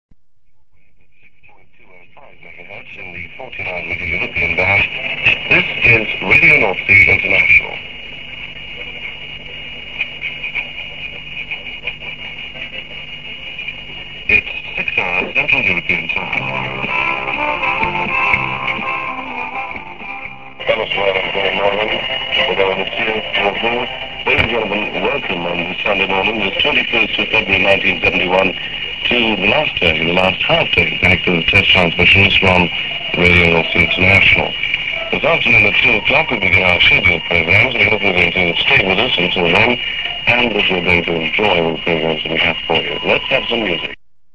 on the last day of test transmissions for Radio Northsea International, 21st February 1971